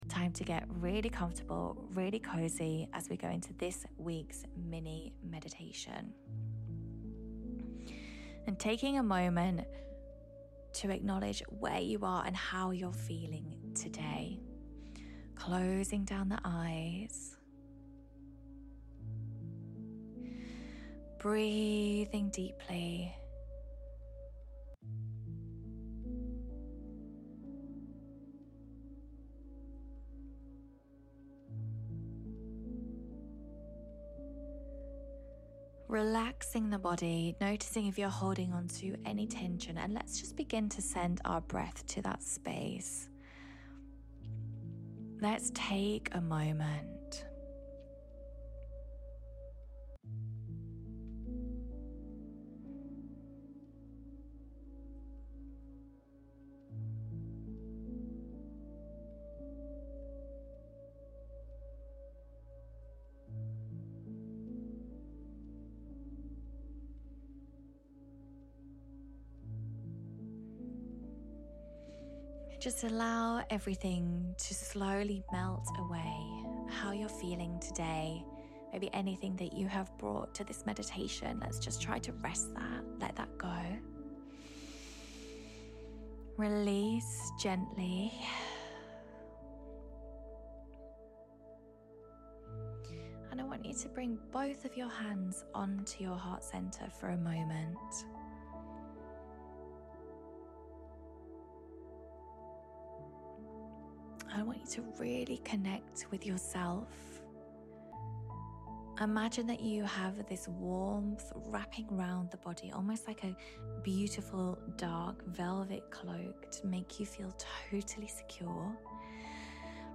Todays Meditation is all about allowing the energy that has been holding you back from making life decisions that you fully deserve.